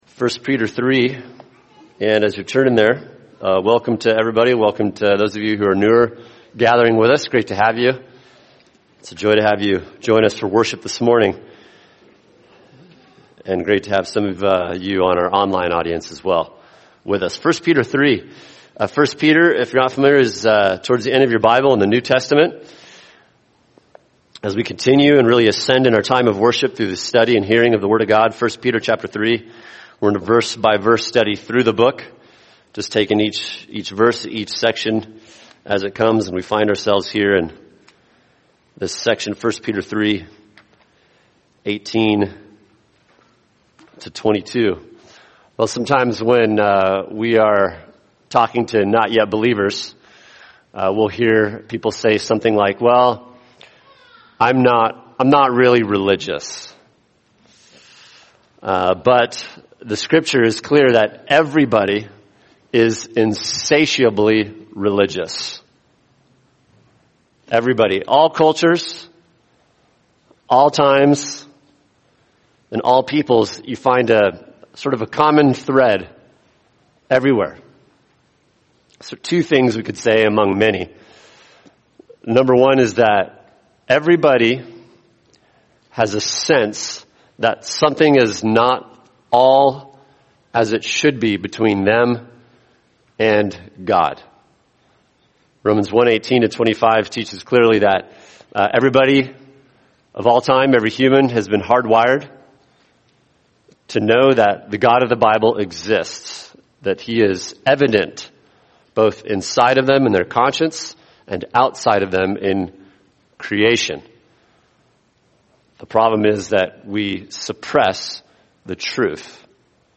[sermon] 1 Peter 3:18 The Glorious Accomplishment of Christ’s Suffering | Cornerstone Church - Jackson Hole